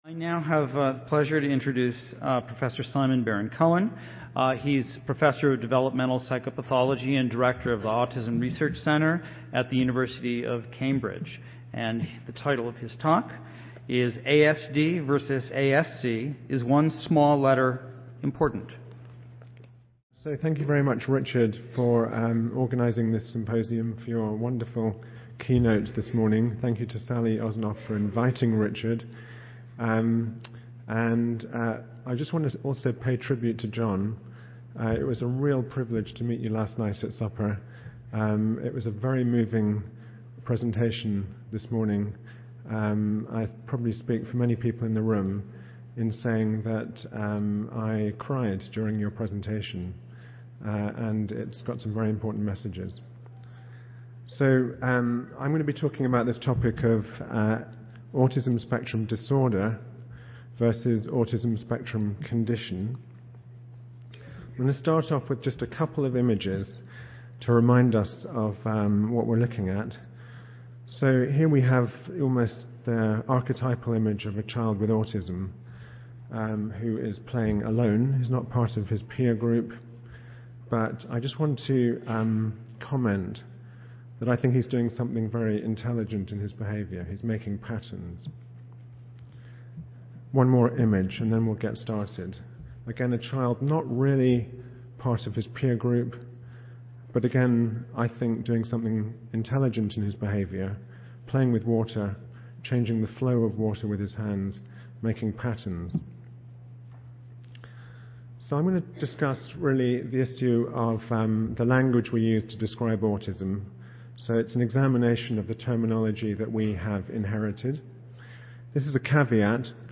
Grand Ballroom B (Grand America Hotel)
Recorded Presentation